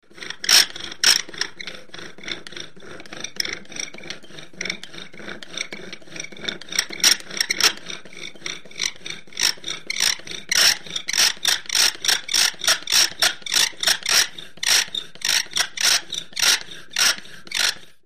Metal File Scraping; Metal Scraping Against Metal, Sawing Motion With High Creaks, Squeaks. Close-up.